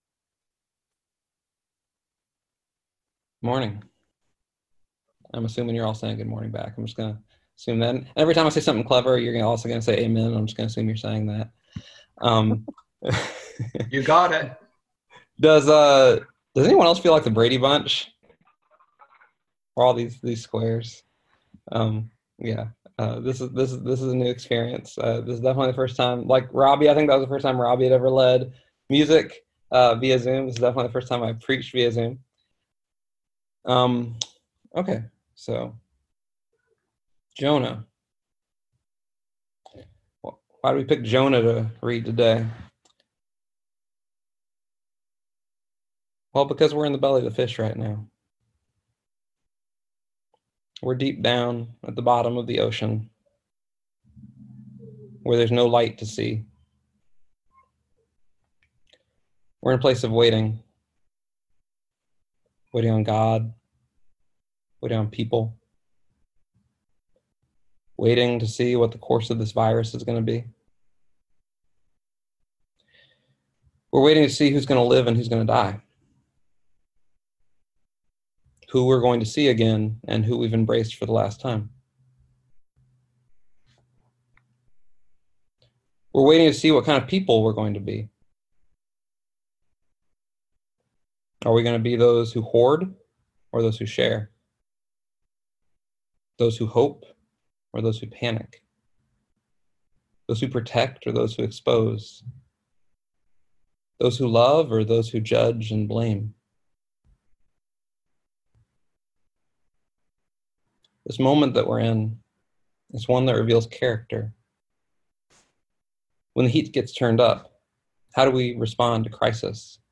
Listen to the most recent message from Sunday worship at Berkeley Friends Church, “The God of the Depths.”